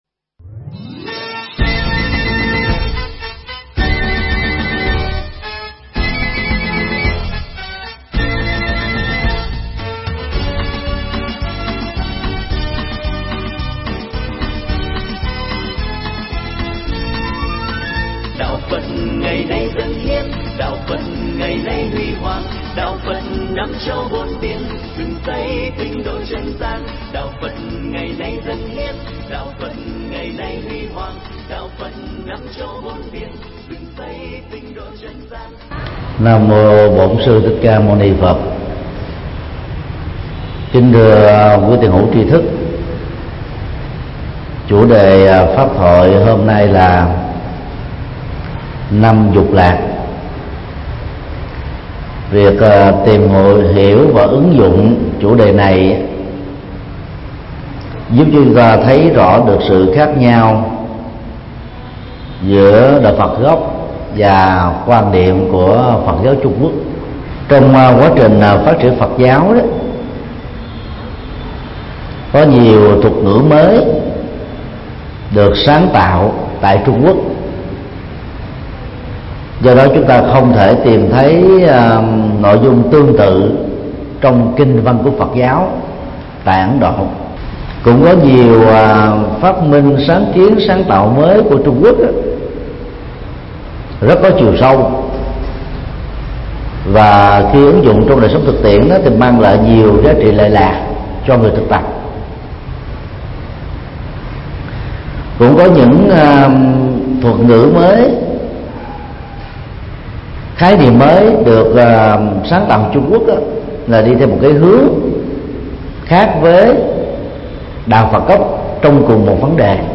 Năm dục lạc – Mp3 Thầy Thích Nhật Từ Thuyết Giảng
Mp3 Thuyết Pháp Năm dục lạc – Thầy Thích Nhật Từ Giảng tại chùa Giác Ngộ 92 Nguyễn Chí Thanh, Phường 3, Quận 10, ngày 10 tháng 5 năm 2015